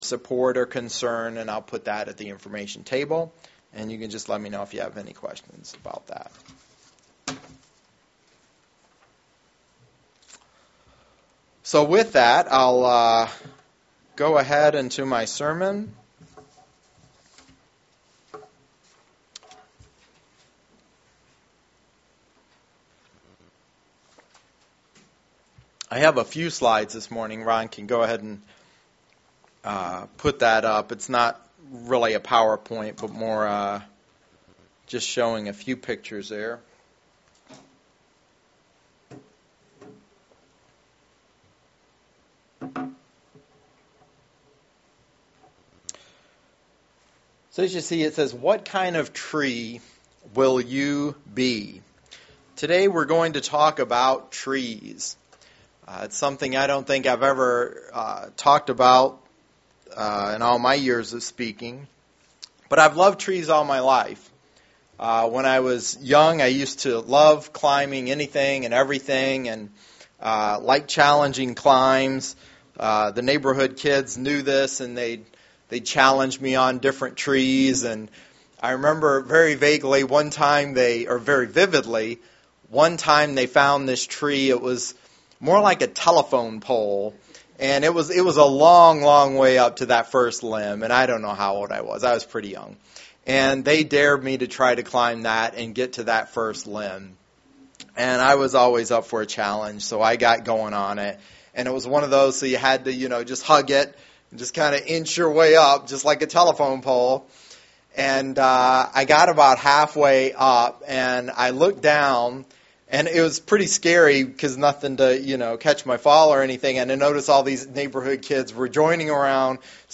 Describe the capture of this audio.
Given in Lansing, MI